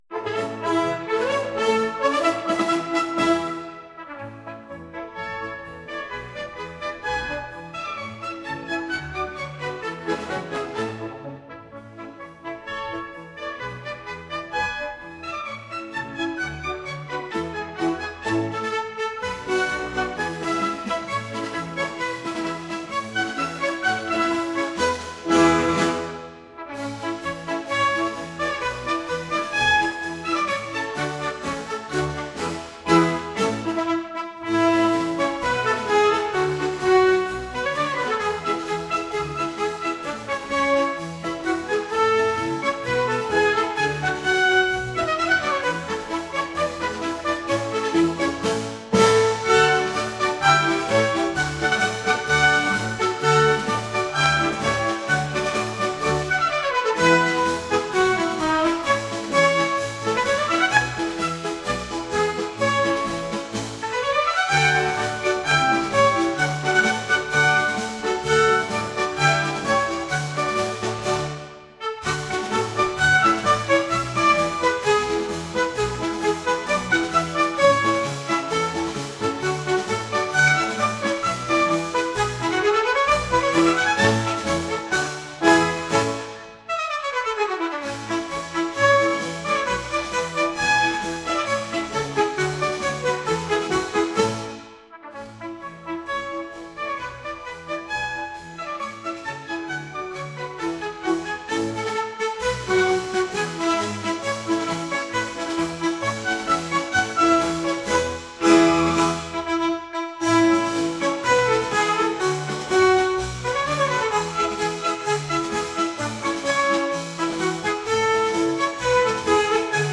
> classical military march, orchestral music, bravura melody, wind instruments
Тянет на марш, ящитаю.